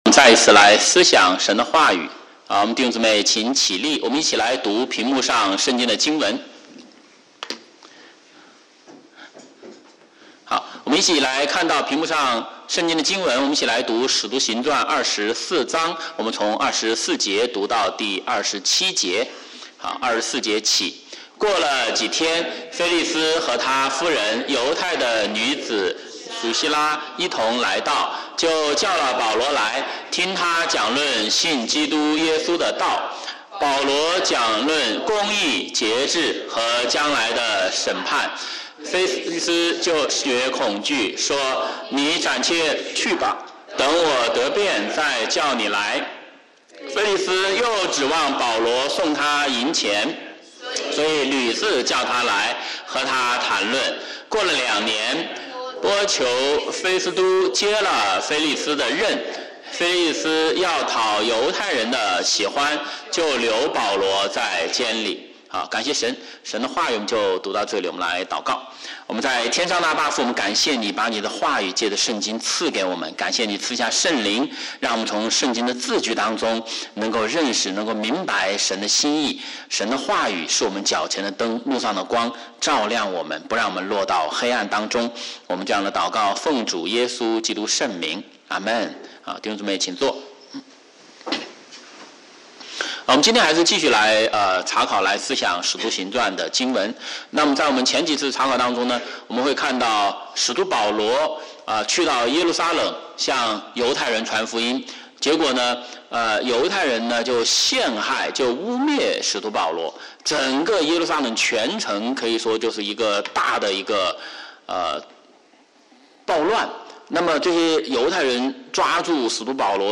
Sunday Sermons (Chinese)